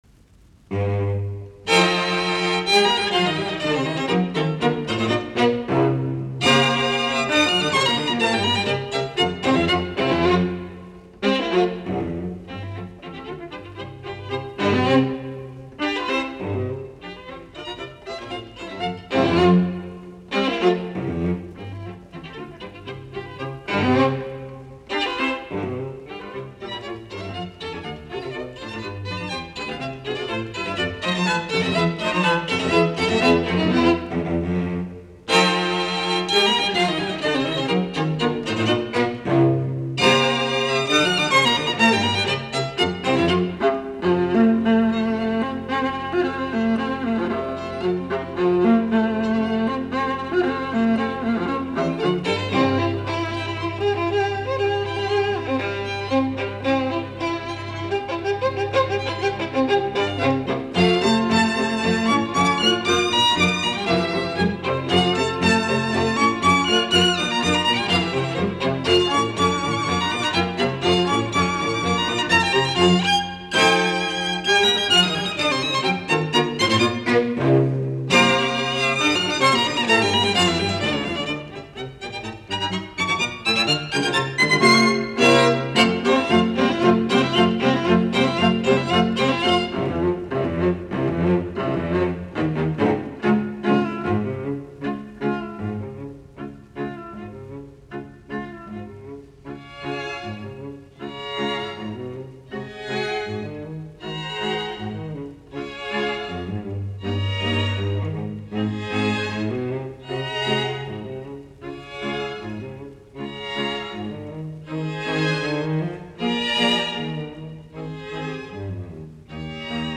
Kvartetot, jouset, nro 1, e-molli
2. Allgro moderato a la polka
Soitinnus: Viulut (2), alttoviulu, sello.